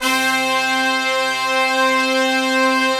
C4 POP BRA.wav